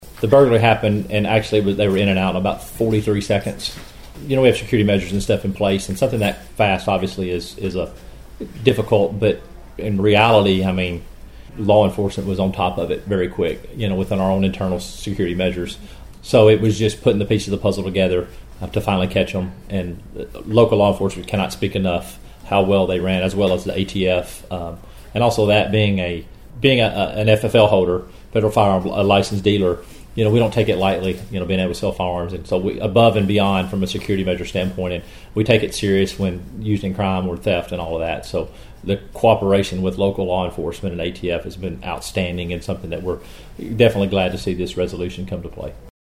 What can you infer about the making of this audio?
During a press event Monday at Final Flight Outfitters